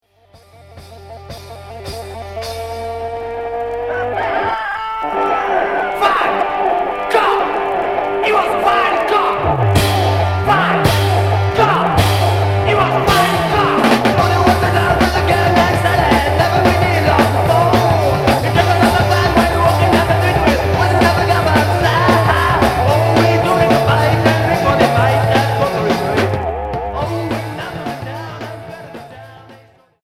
Heavy rock Stoogien Troisième 45t retour à l'accueil